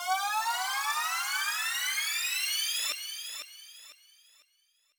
MB Drop Effect (3).wav